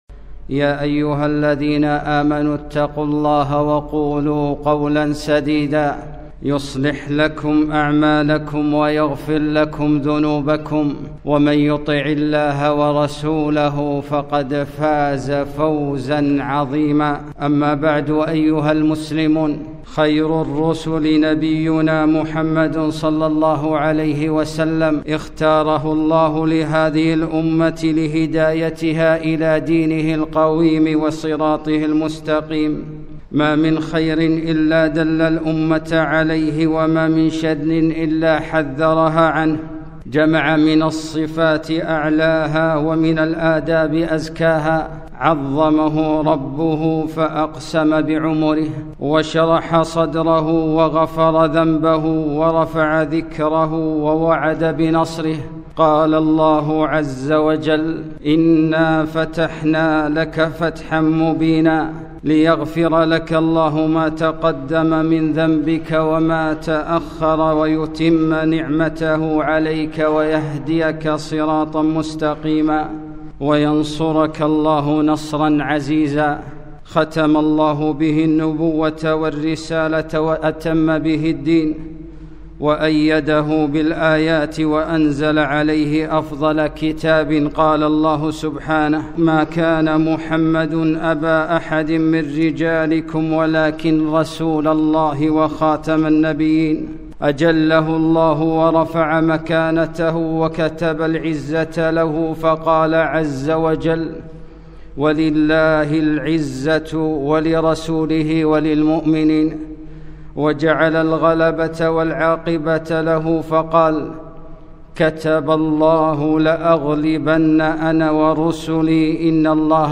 خطبة - الزمه فثم الجنة